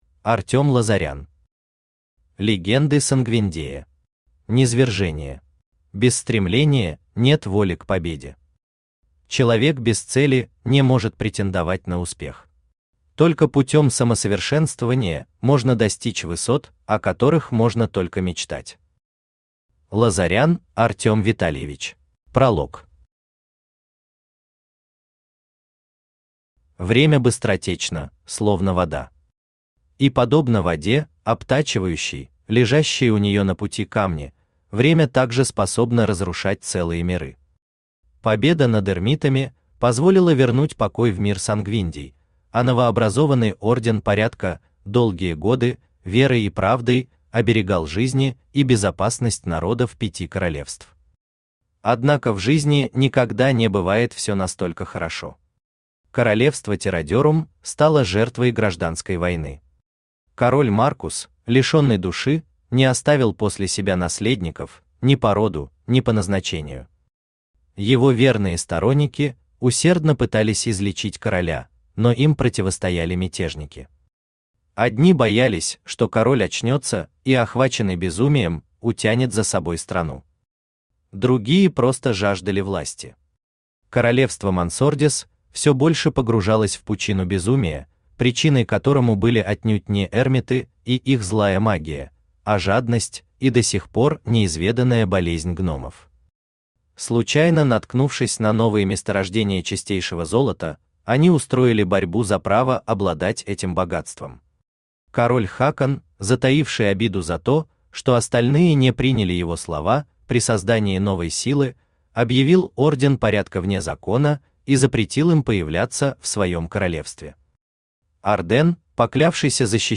Аудиокнига Легенды Сангвиндея. Низвержение | Библиотека аудиокниг
Читает аудиокнигу Авточтец ЛитРес.